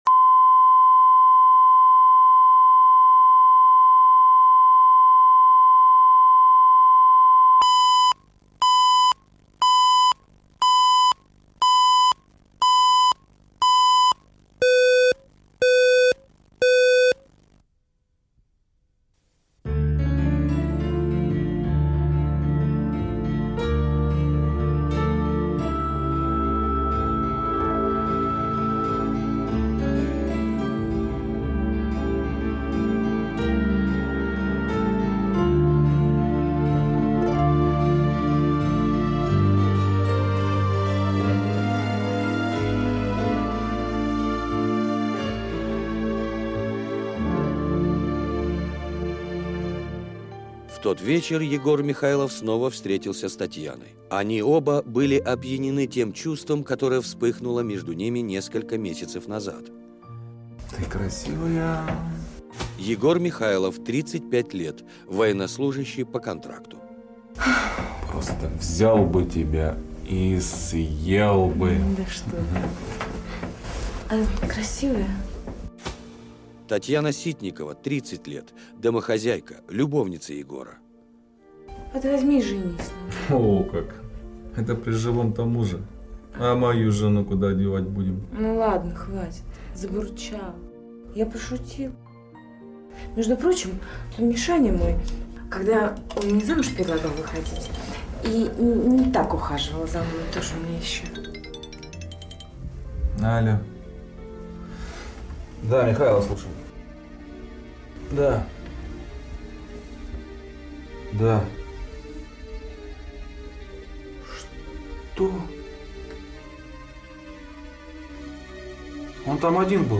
Аудиокнига Последняя ошибка | Библиотека аудиокниг